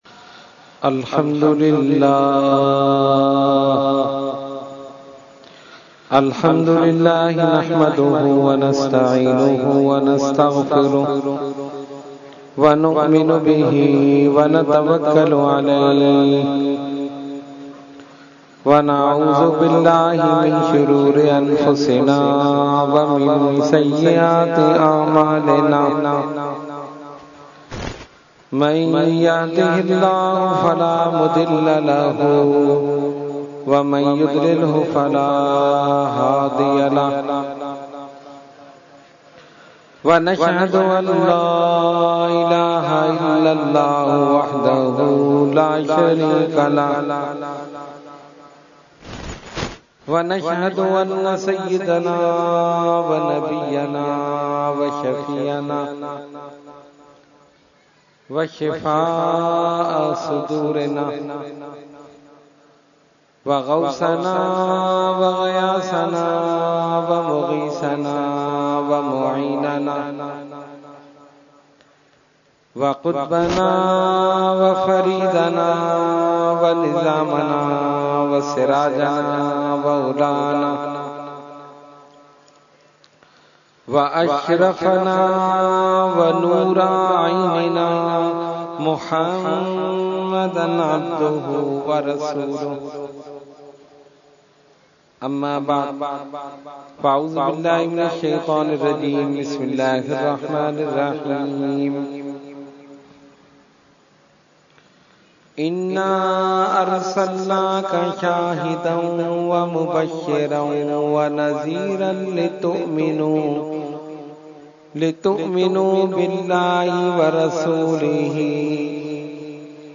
Category : Speech | Language : UrduEvent : Muharram 2017